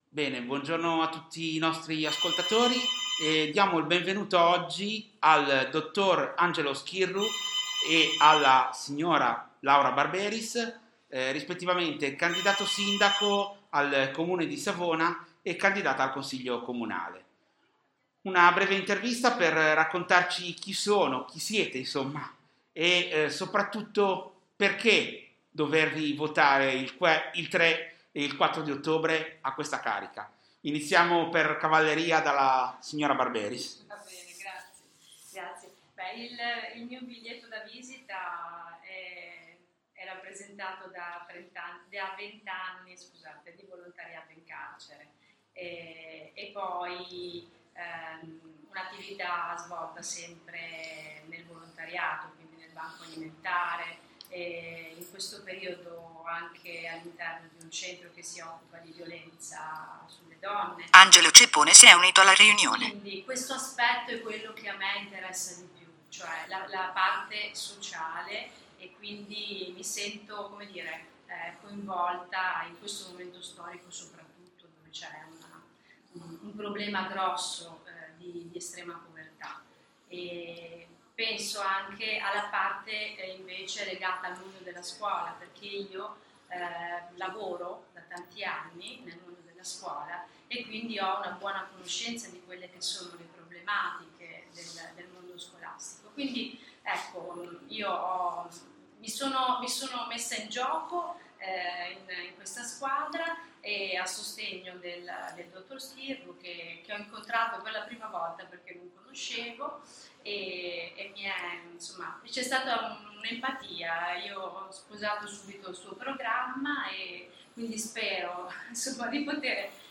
Intervista al candidato Sindaco alle amministrative comunali